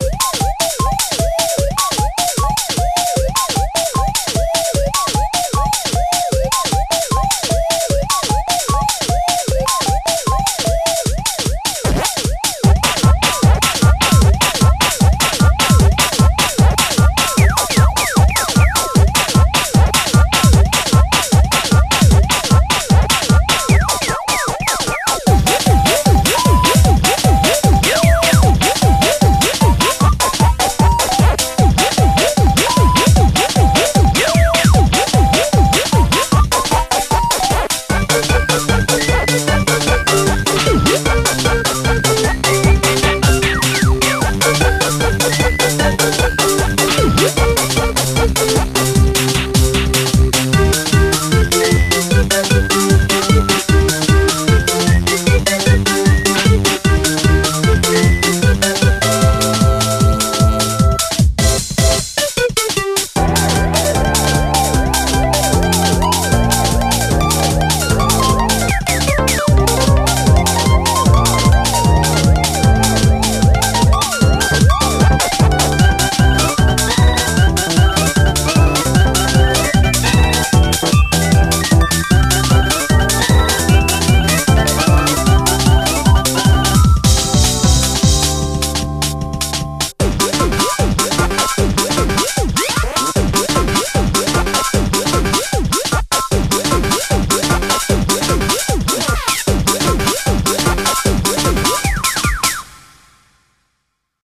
BPM152
Audio QualityPerfect (High Quality)
Pacman style!